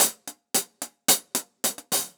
Index of /musicradar/ultimate-hihat-samples/110bpm
UHH_AcoustiHatC_110-02.wav